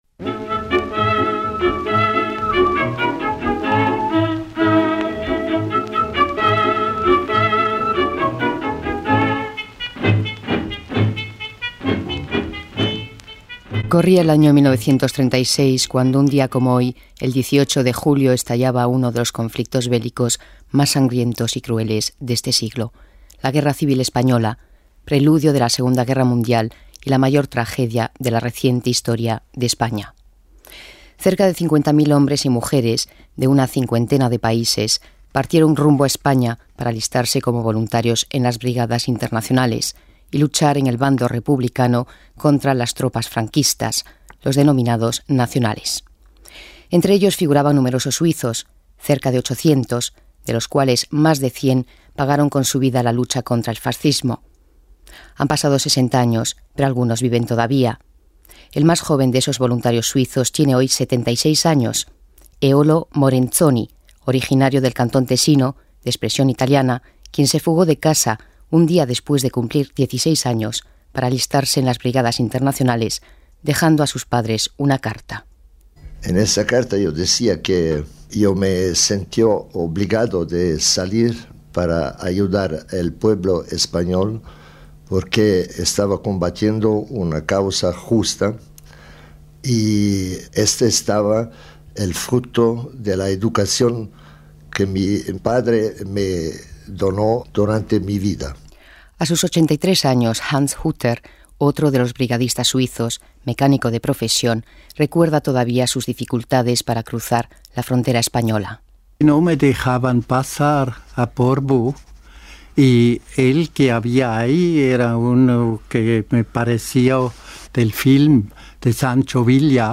Testimonios de los últimos brigadistas suizos